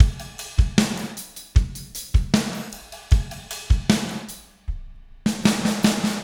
Spaced Out Knoll Drums 05 Fill.wav